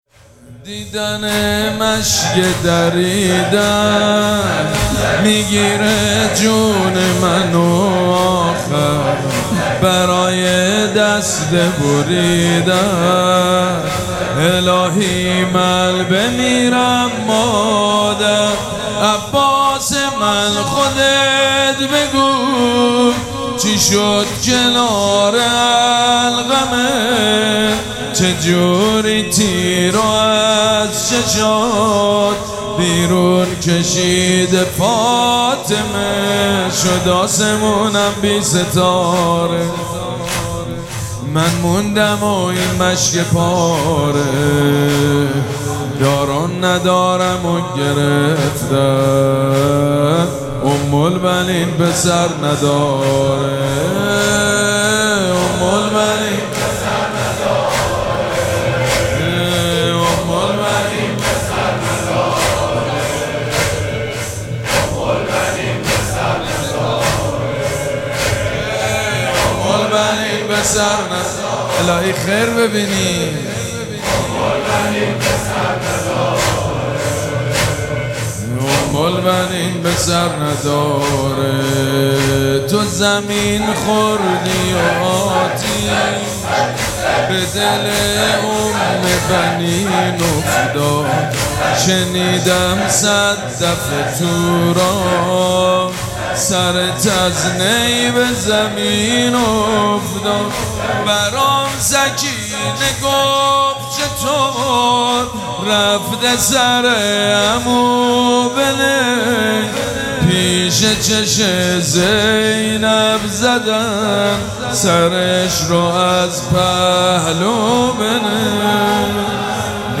شام وفات حضرت ام البنین(س)
حاج سید مجید بنی فاطمه